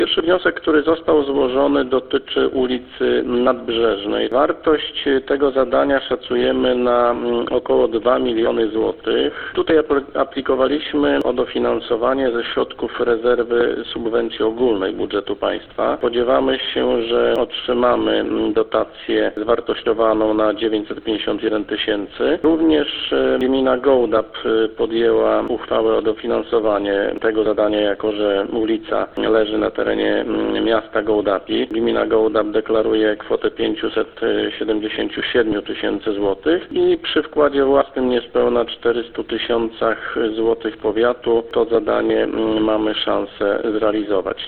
– Starostwo powiatowe stara się o pozyskanie dofinansowania na prace modernizacyjne ze środków zewnętrznych – przyznaje w rozmowie z Radiem 5 starosta gołdapski Andrzej Ciołek.